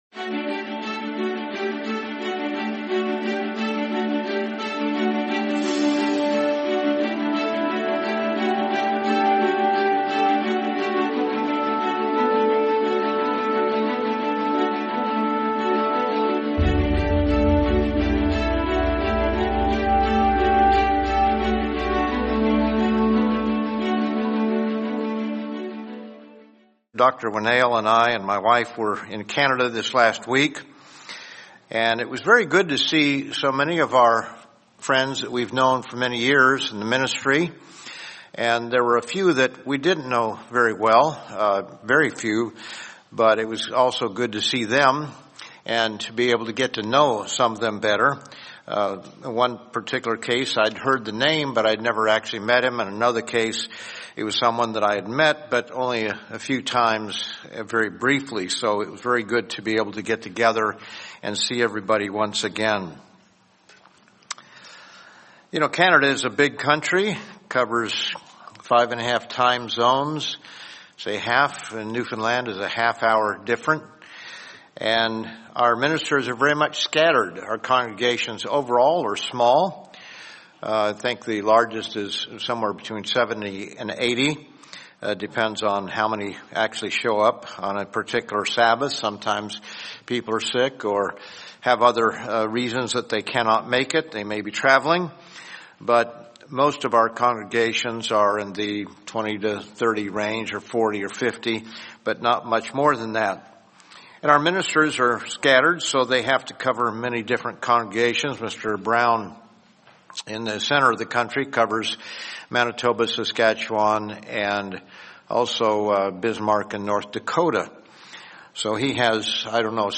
Sermon The Question of Birthdays